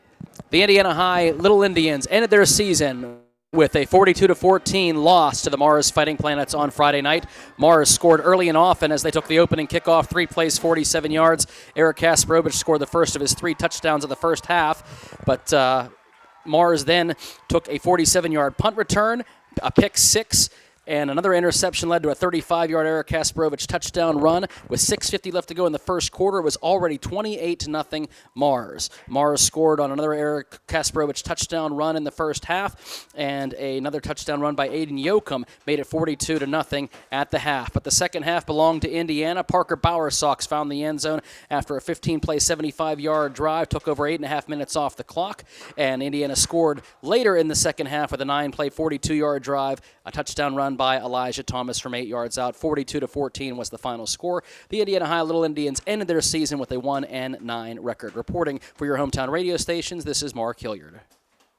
hsfb-indiana-vs-mars-recap.wav